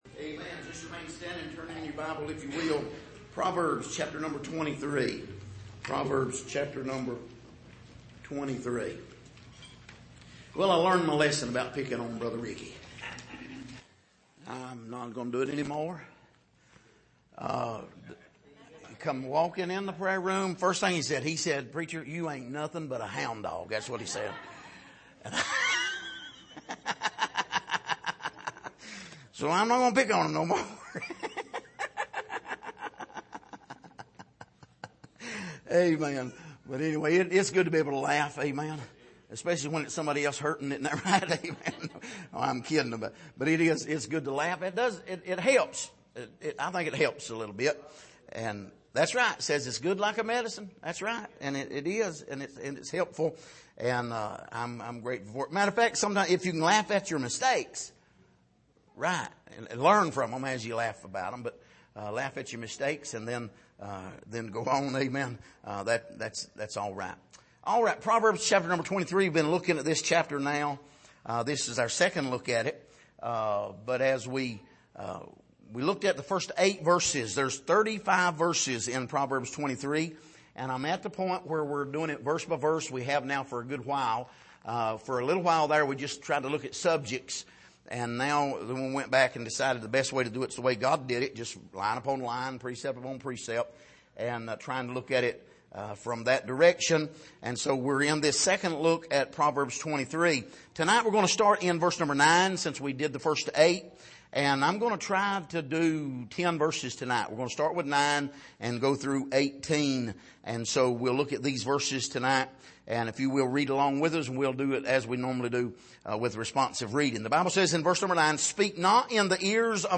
Passage: Proverbs 23:9-18 Service: Sunday Evening